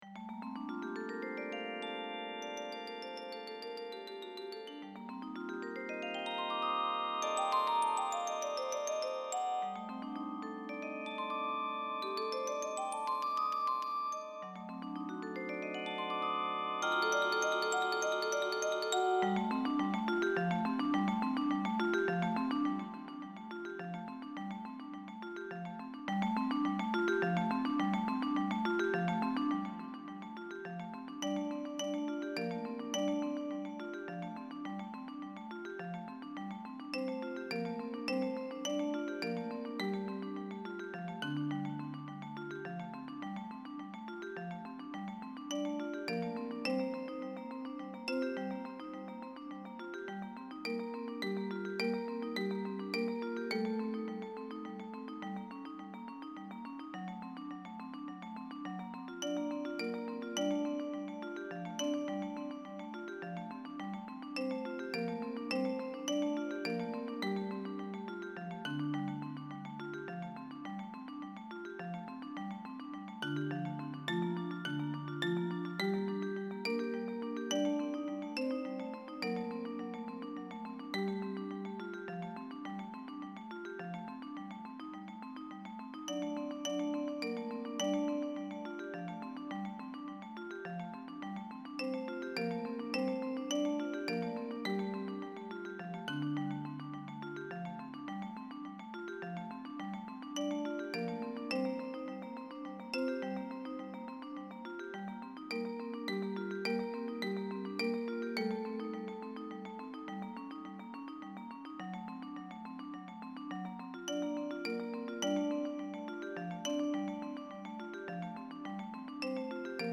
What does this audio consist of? Instrumentation: percussions classical